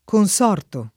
consorto [ kon S0 rto ]